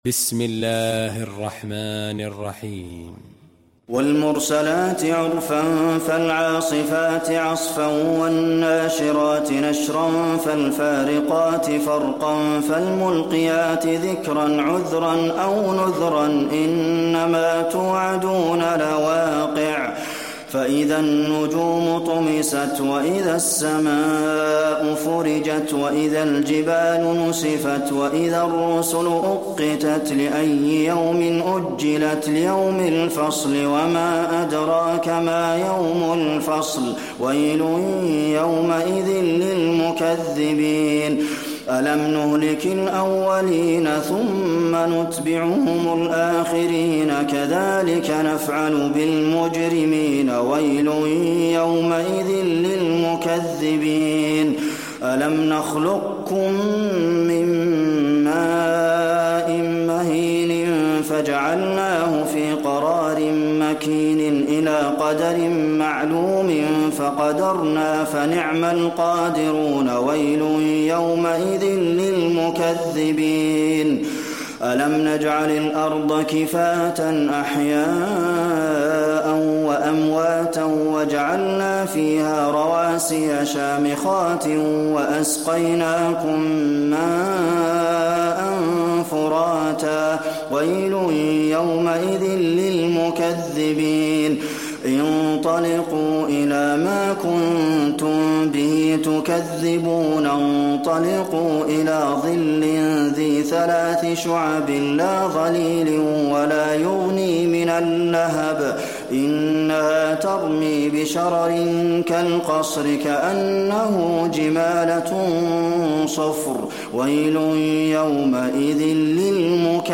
المكان: المسجد النبوي المرسلات